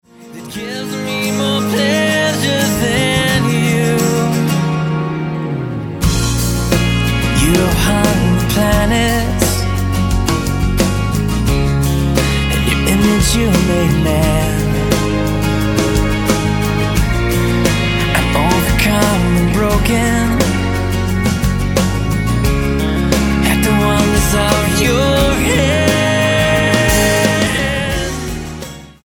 STYLE: Roots/Acoustic
signature harmonies and driving guitars